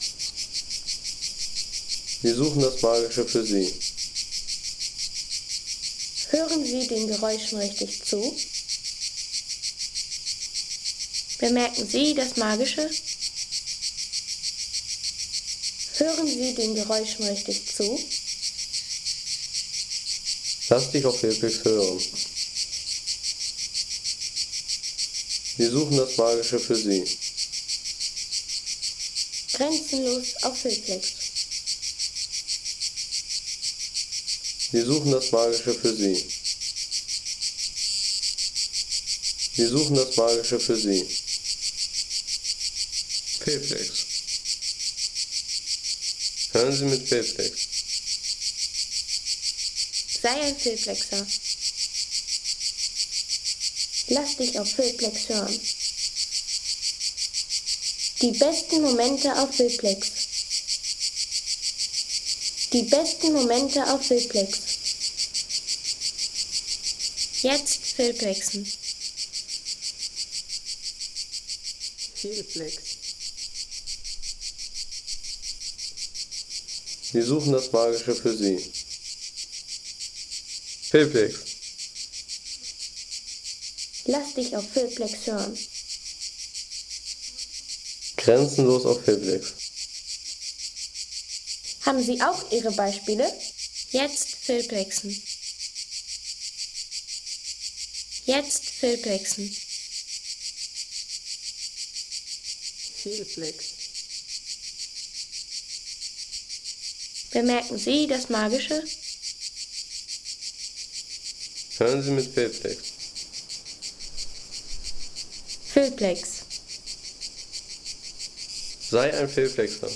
Zikadengesang
Zikadengesang – Die akustische Signatur des Sommers.